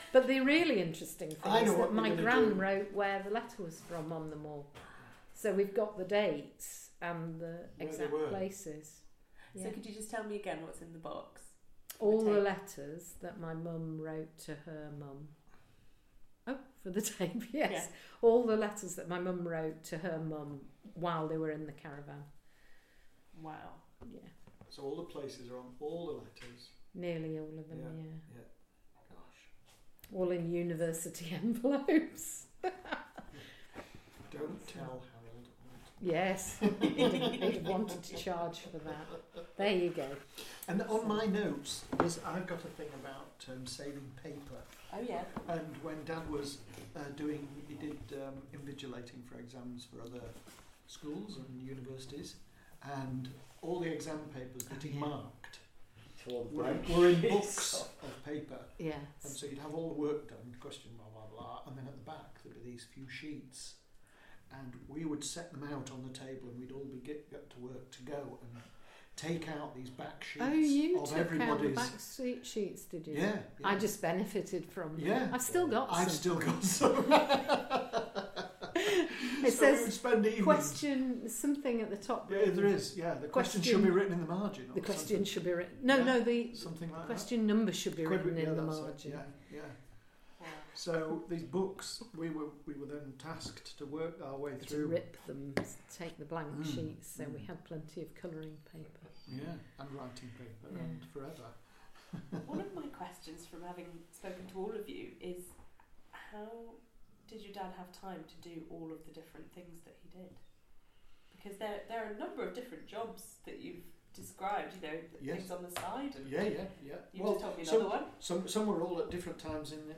Digital recording of oral history interview conducted with named interviewee (see item title) as part of National Lottery Heritage funded, "Dialect and Heritage" Project.